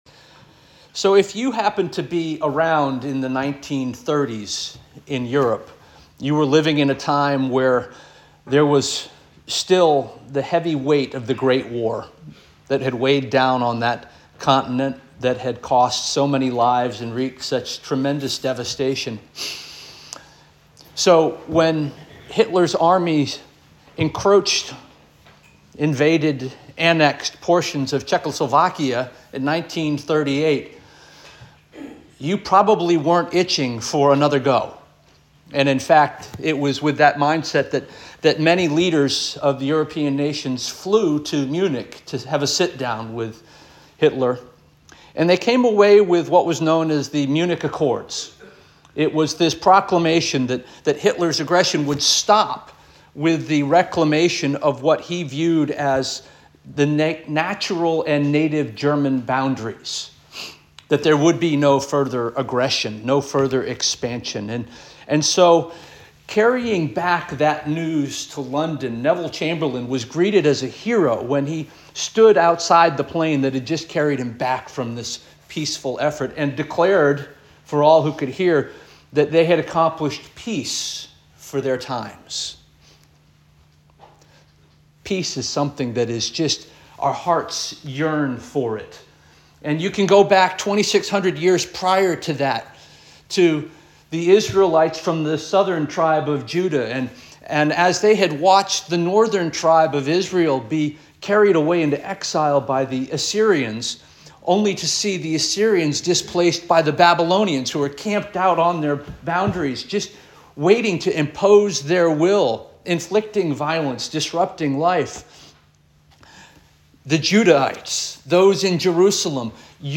December 8 2024 Sermon